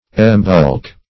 Embulk \Em*bulk"\